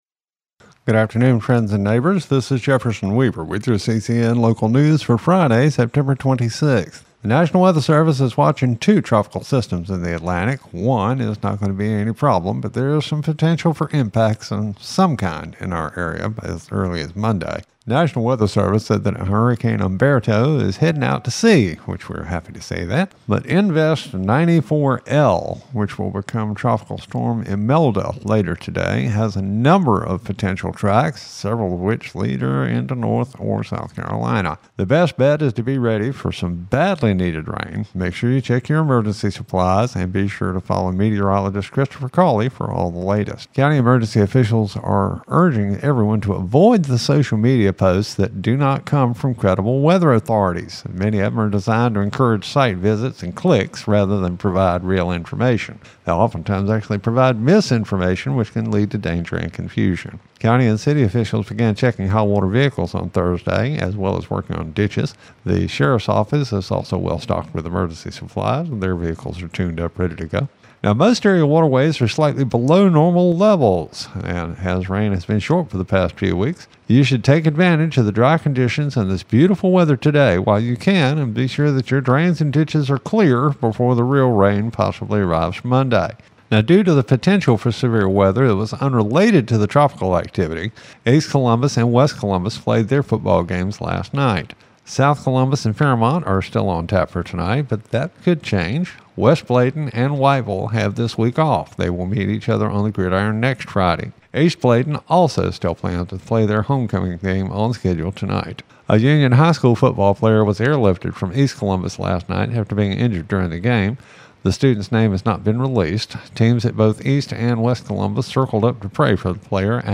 CCN Radio News — Afternoon Report for September 26, 2025